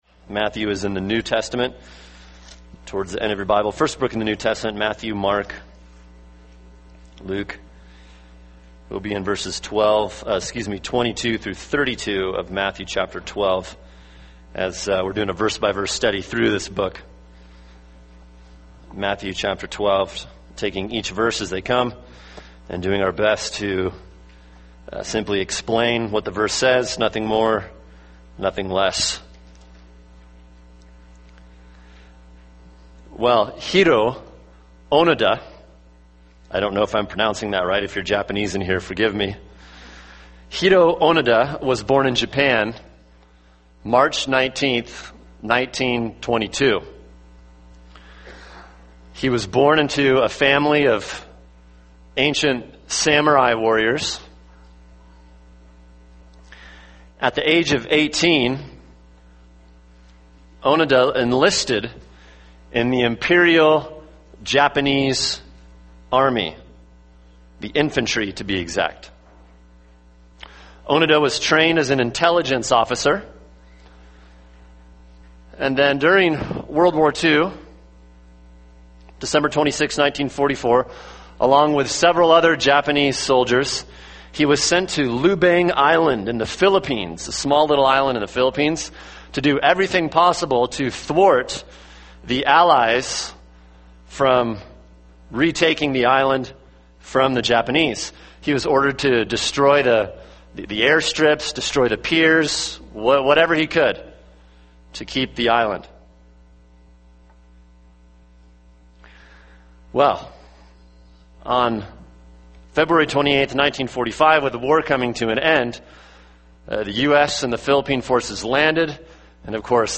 [sermon] Matthew 12:22-32 – The Dangers of Rejecting Christ (part 1) | Cornerstone Church - Jackson Hole